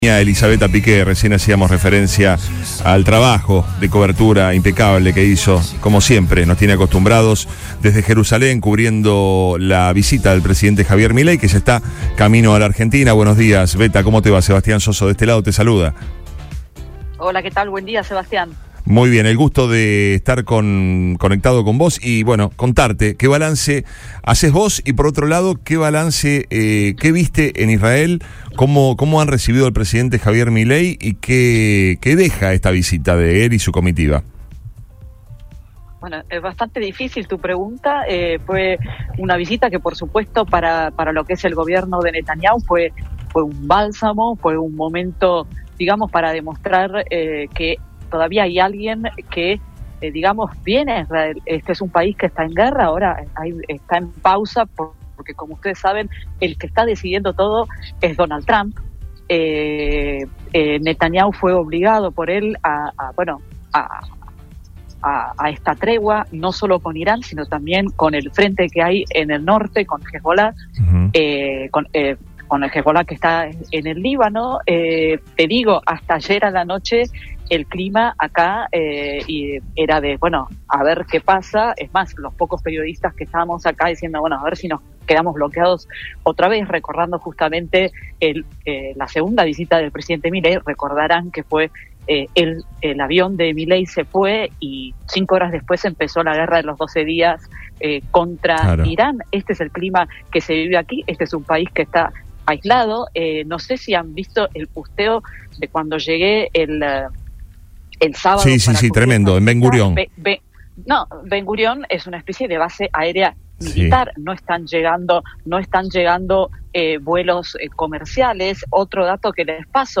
Según relató la propia periodista en diálogo con radio argentina, el mandatario evitó responder y continuó su recorrido sin emitir declaraciones.